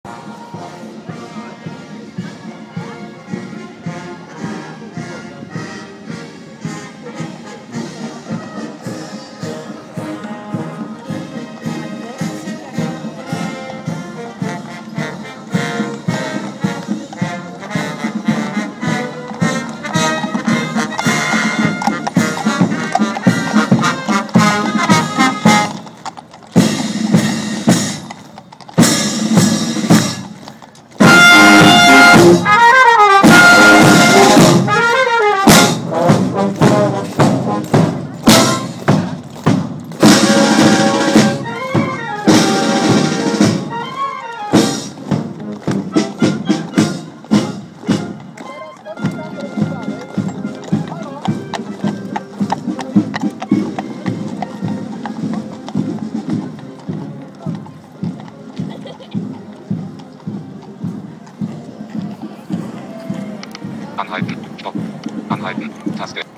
Mehr zufällig gerieten wir in den riesigen Bohei rund um die Wachwechsel vorm Buckingham Palace , ließen die Kapelle an uns vorüberziehen ( Audio: Kapelle vorm Wachwechsel am 29.07.10 ), um dann im Hyde Park Eichhörnchen zu füttern.